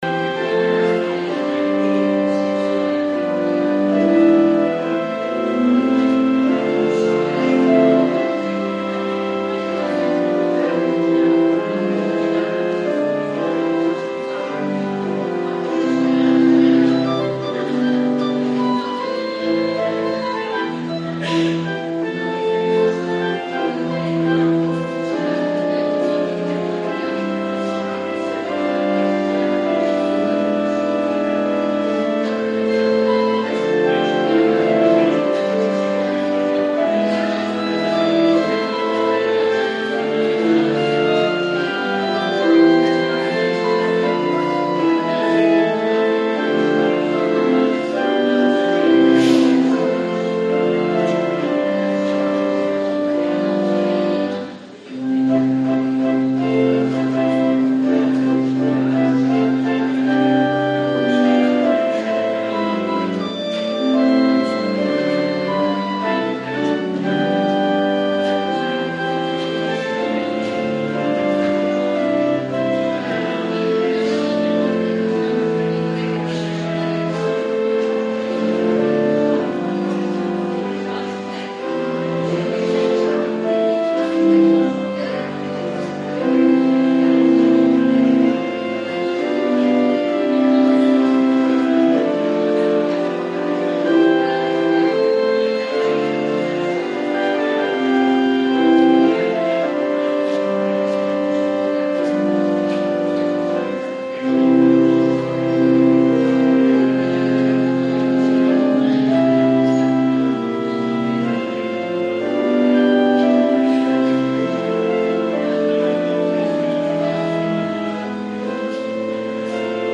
De dienst wordt ondersteund door Gospelkoor RECLAIM uit Klundert Thema van de dienst is; Onbreekbare liefde Dienst in Hooge Zwaluwe met na afloop koffie of thee en kunnen we elkaar spreken in de Rank Luister hier deze dienst terug